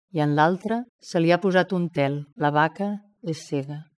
speech-harmonicb
harmonic speech synthesis sound effect free sound royalty free Memes